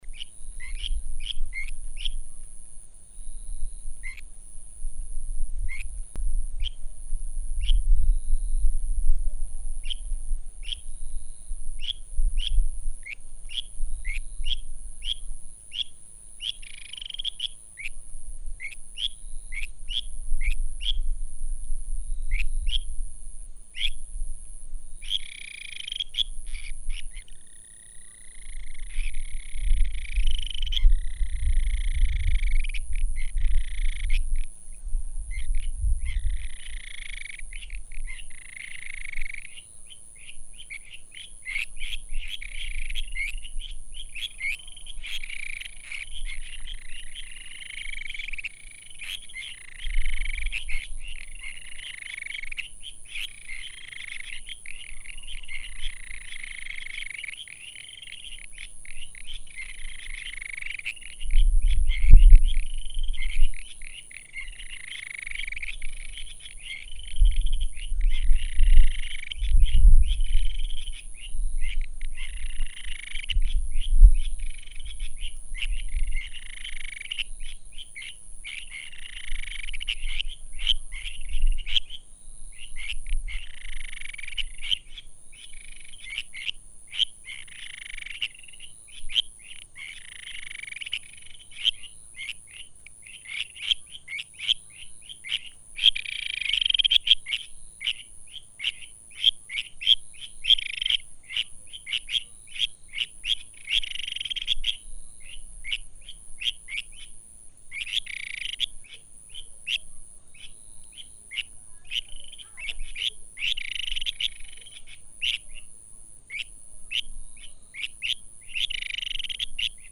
日本樹蛙 Buergeria japonica
花蓮縣 壽豐鄉 池南森林遊樂區
次生林旁草叢
2隻競叫